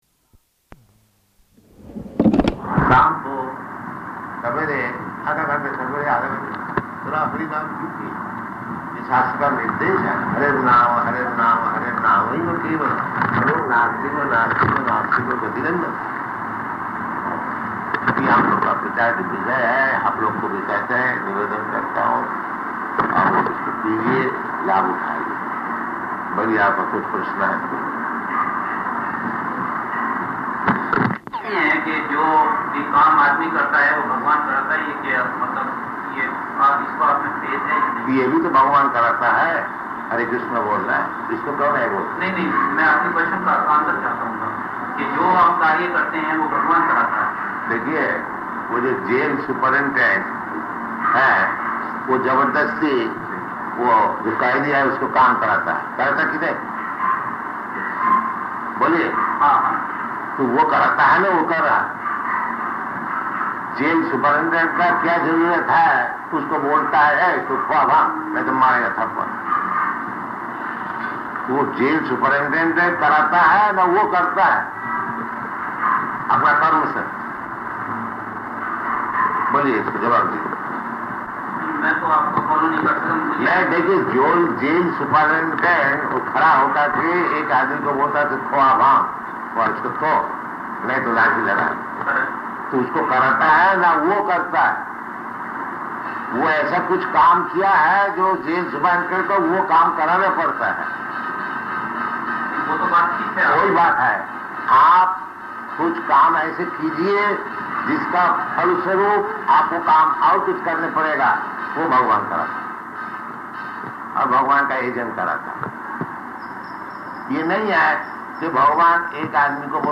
Room Conversation in Hindi
Location: Madras
Room ConversationLecture in Hindi [Partially Recorded]